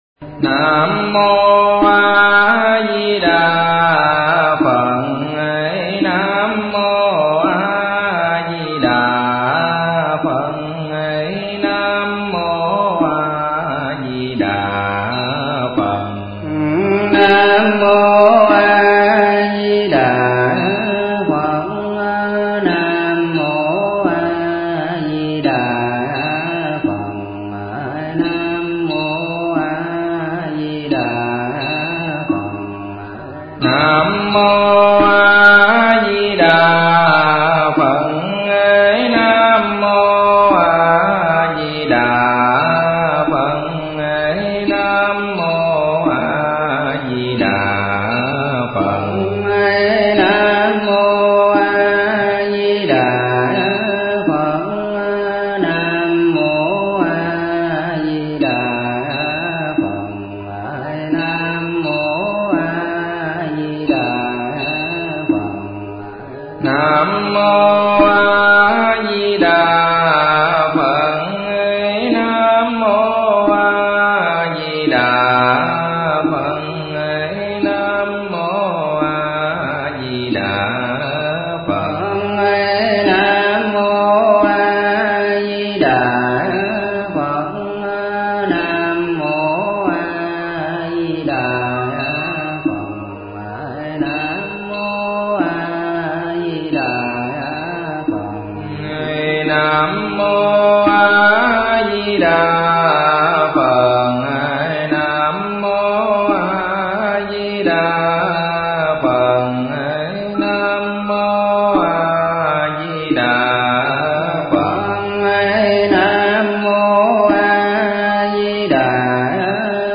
Niệm Phật Kinh Hành – Kinh tụng